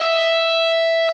guitar_014.ogg